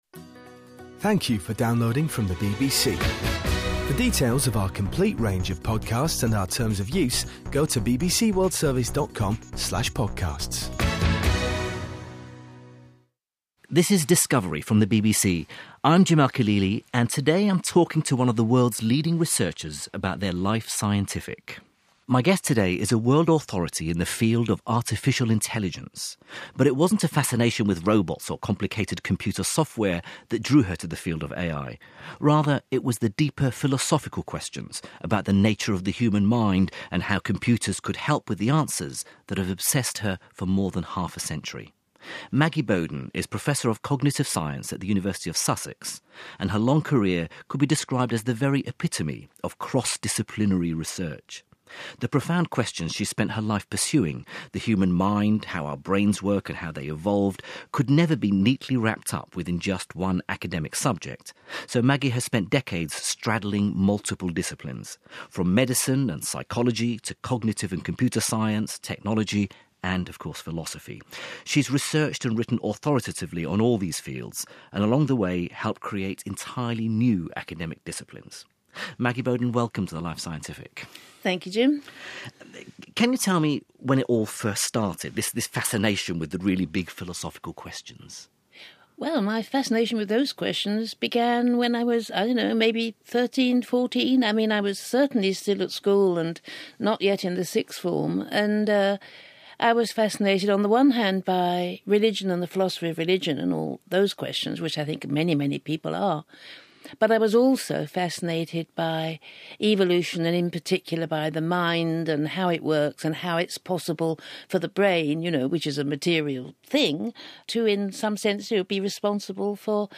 A few autobiographical details are included in my interview for the BBC Radio-4 programme “The Life Scientific” (October 2014).